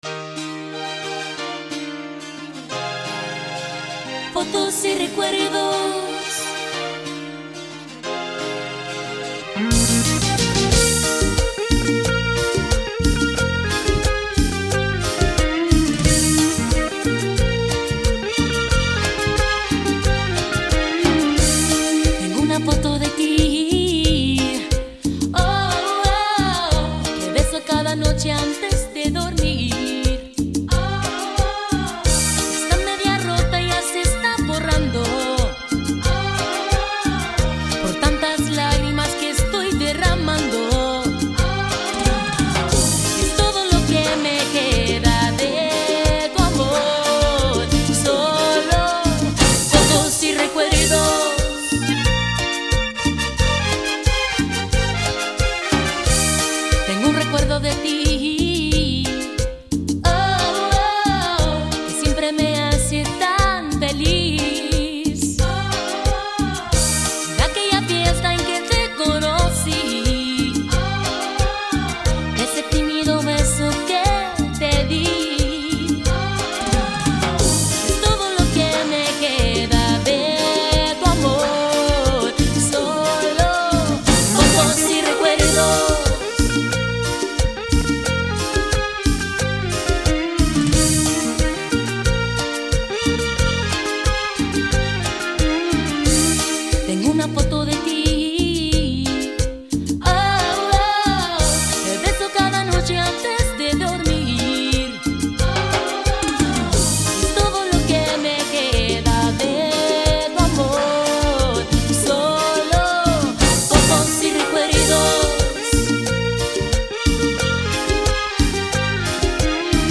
texmex